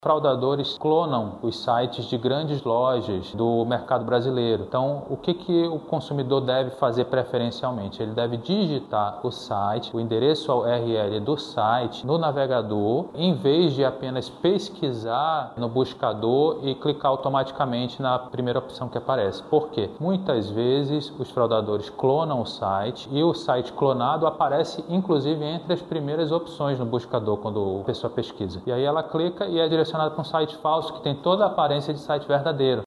Outro golpe frequente é o dos sites falsos, explica ainda o delegado.